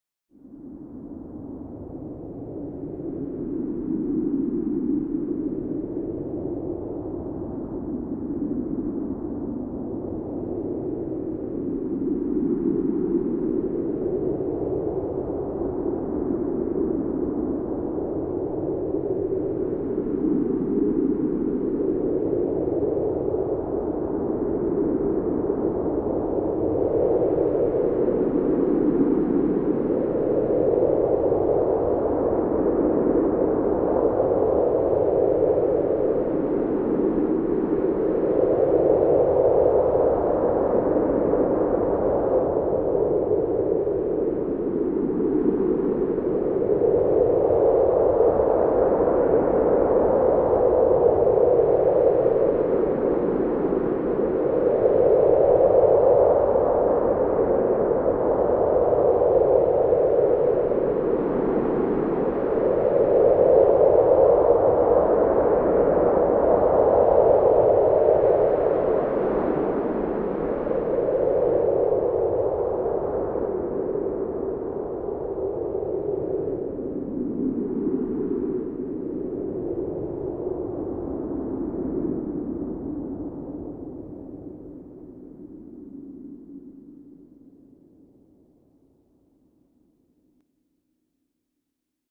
Cosmic Wind Sound Effect
Ethereal wind ambiance inspired by outer space. Ideal for sci-fi, fantasy, and abstract audio projects needing a mysterious and otherworldly atmosphere.
Cosmic-wind-sound-effect.mp3